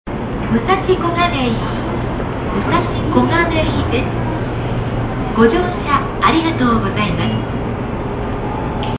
武蔵小金井駅駅名連呼駅名連呼です。
「○○〜//○○/です」という言い回しは、総武緩行線まで使用されました。